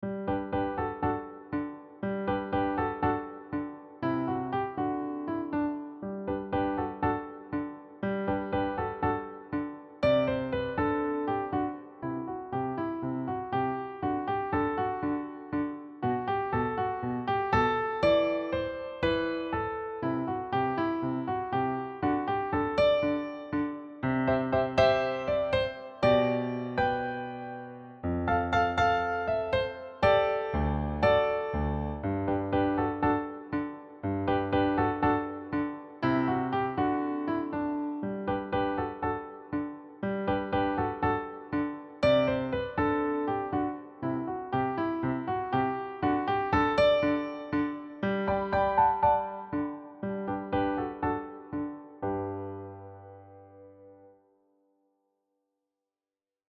Key: G Major
Time Signature: 4/4